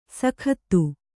♪ sakhattu